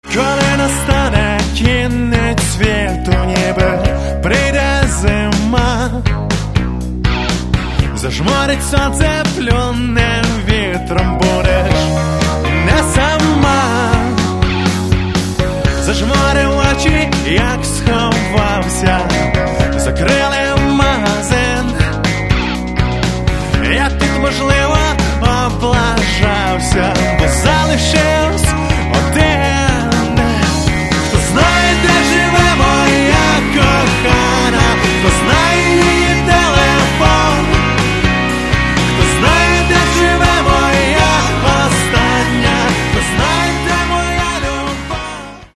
Каталог -> Рок и альтернатива -> Поп рок